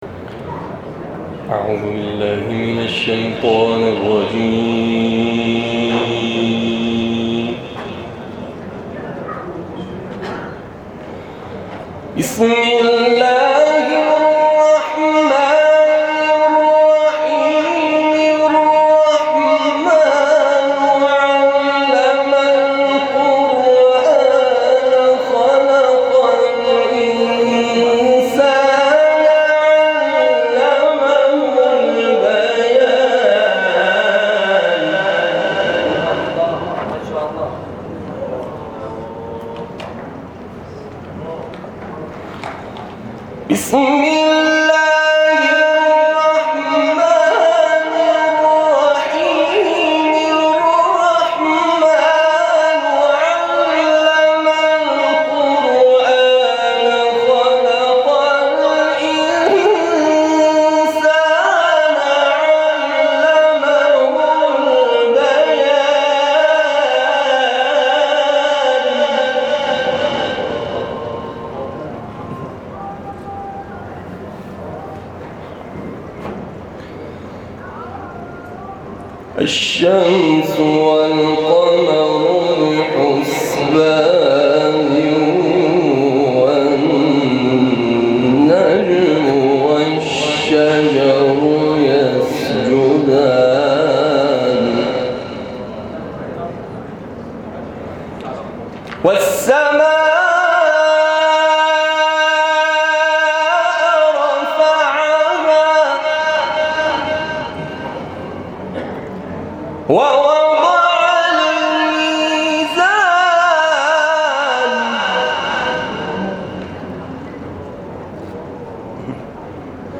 در این مراسم
به تلاوت آیاتی از سوره الرحمن پرداخت
تلاوت